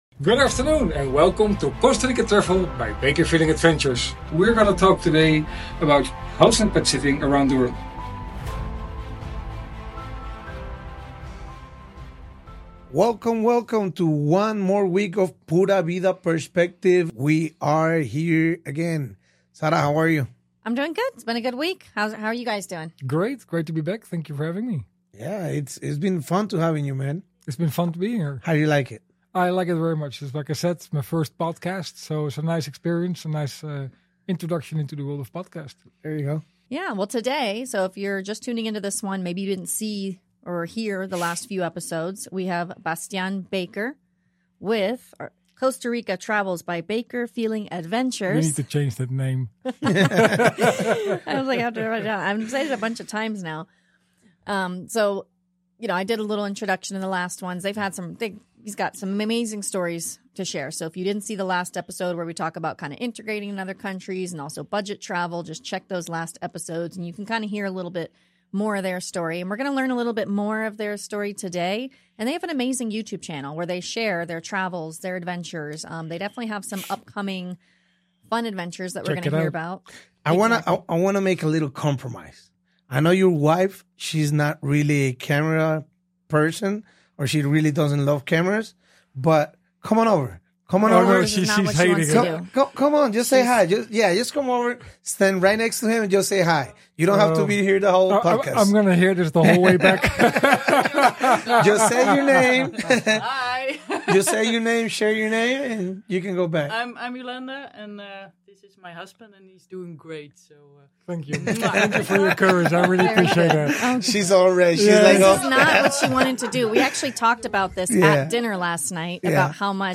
A candid conversation with a man who lives and LOVES to travel.